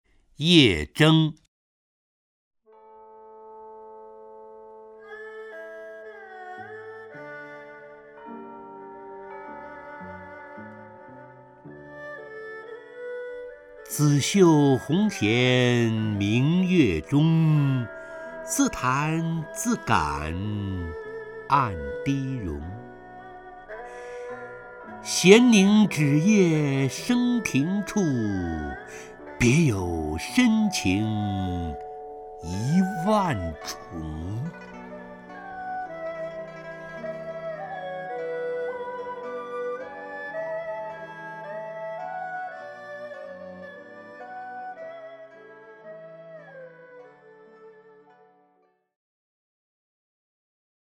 陈醇朗诵：《夜筝》(（唐）白居易) （唐）白居易 名家朗诵欣赏陈醇 语文PLUS